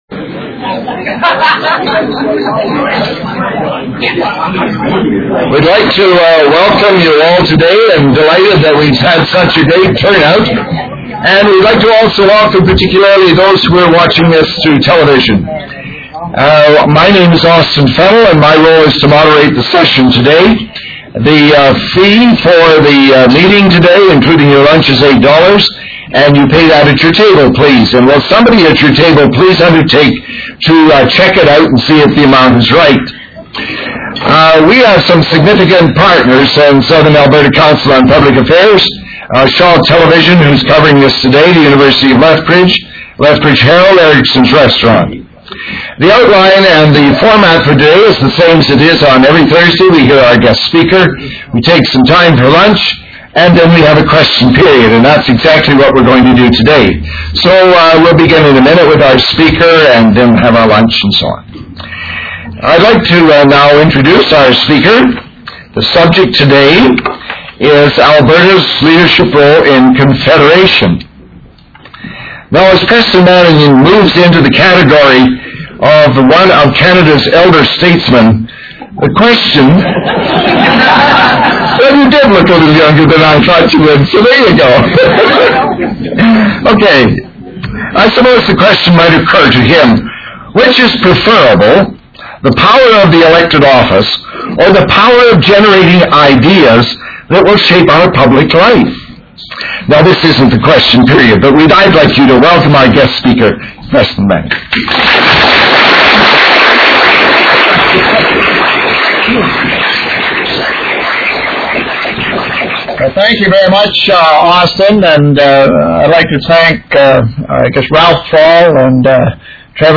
Mr. Manning will raise such questions, invite responses from the audience and provide his own view on how these questions might best be answered. Speaker: Preston Manning Mr. Manning served as a Member of Parliament from 1993 to 2001.